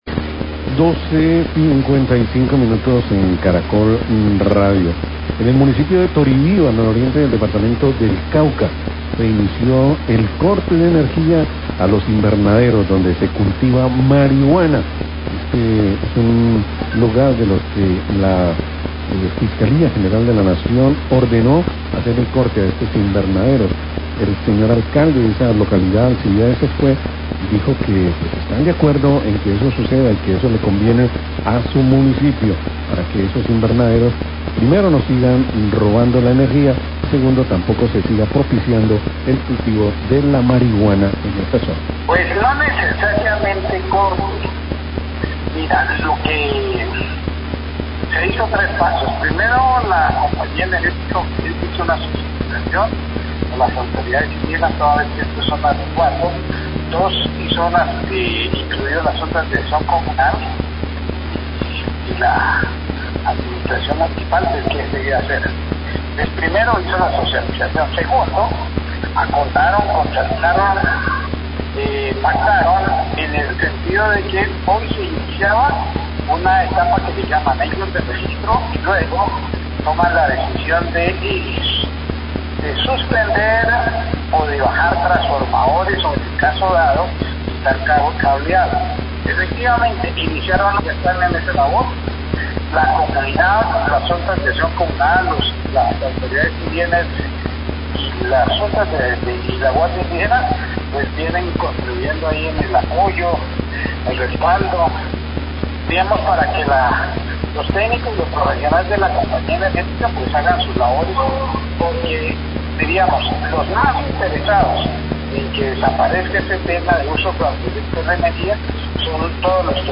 Radio
En Toribío inició el corte del servicio de energía a invernaderos de marihuana en las zonas donde la Fiscalía General ordenó a la Compañía Energética realizar estas suspensiones. Declaraciones del Alcalde de Toribío, Alcibiades Escúe.